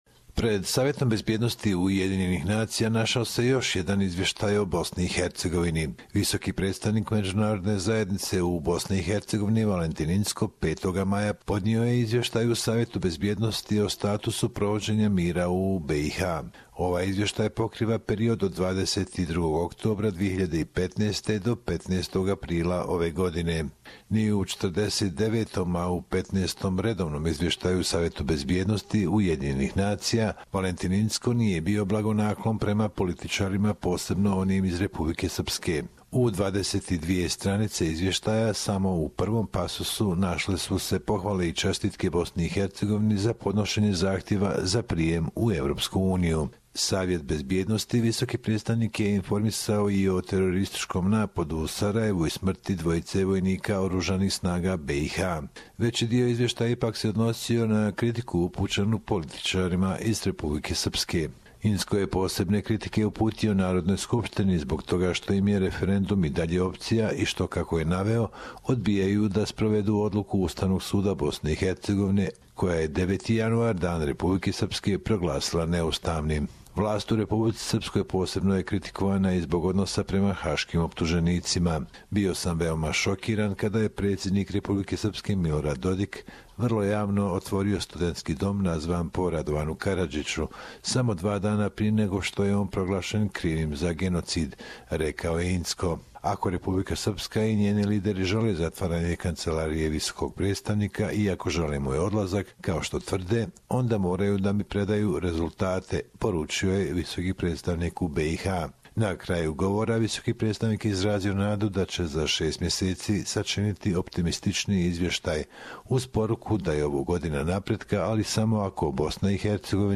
Report.